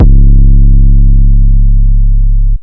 TM88 Bleed808.wav